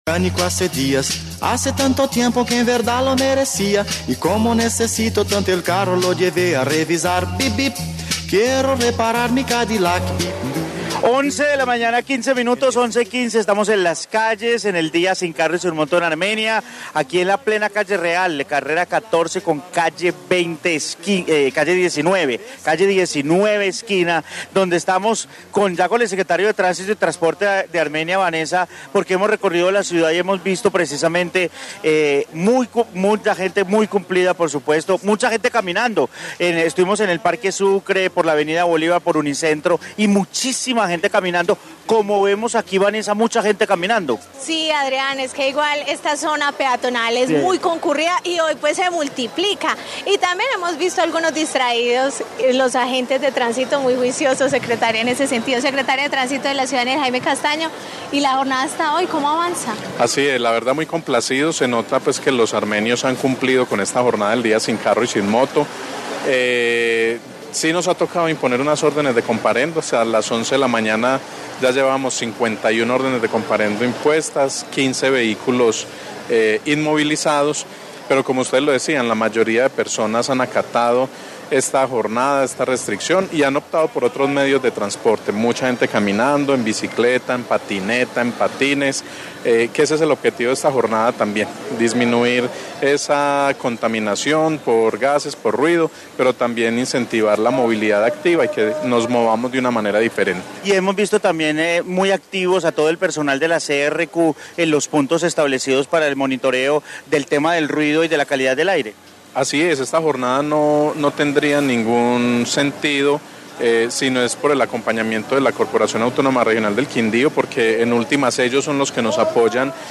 Informe día sin Carro en Armenia